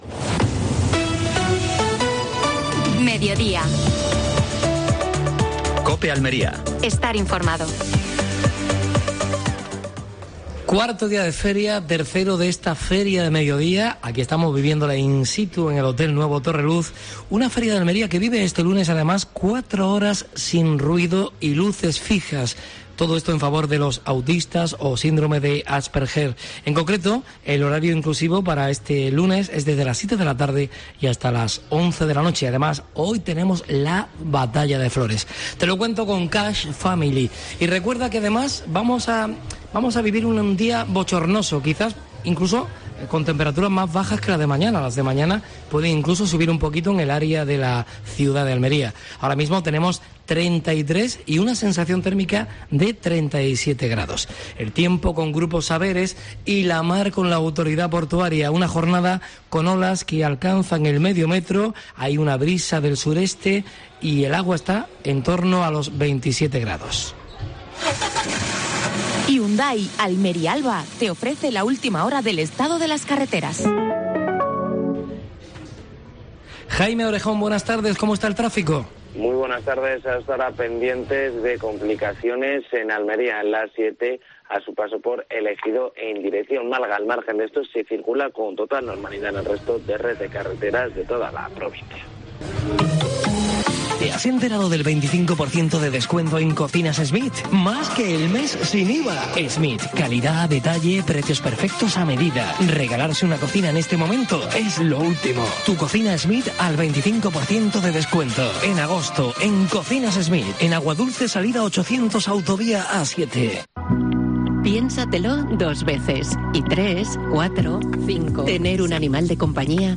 AUDIO: Actualidad en Almería. Programación especial desde el Hotel Torreluz con motivo de la Feria de Almería.